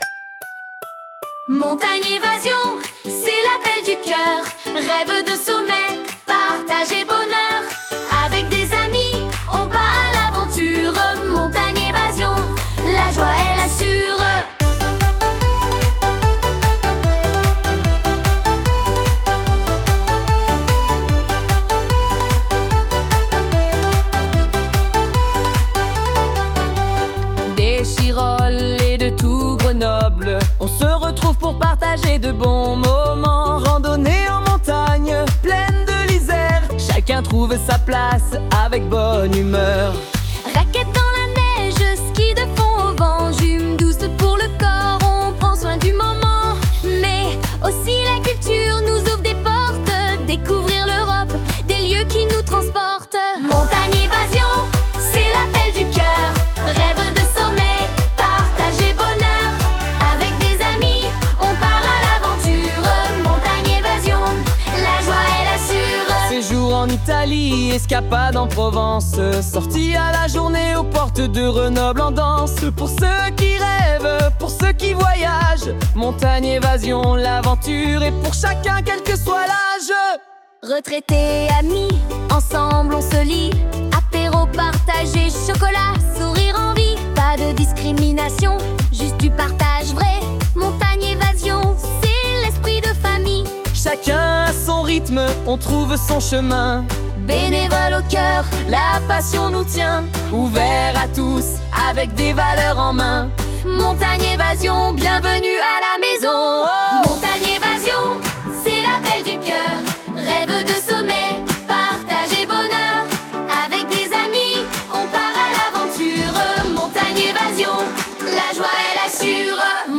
Chant de Montagne Evasion balade joyeuse1